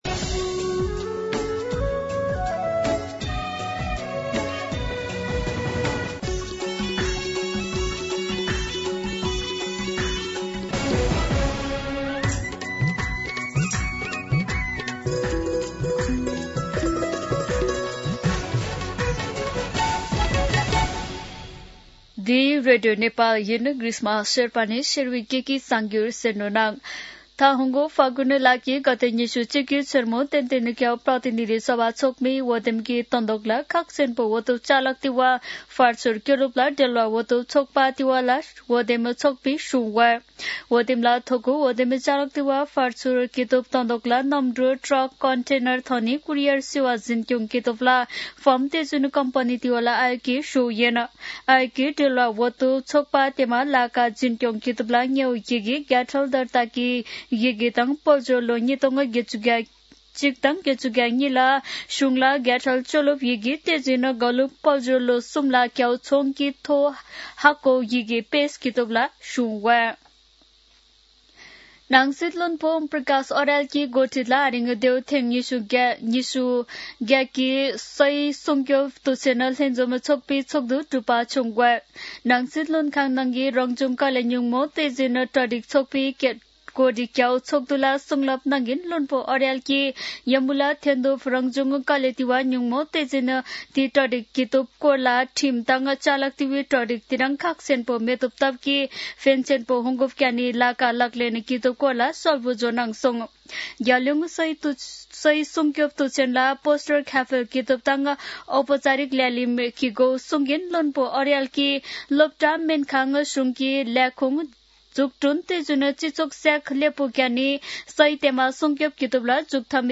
शेर्पा भाषाको समाचार : १८ पुष , २०८२
Sherpa-News-9-18.mp3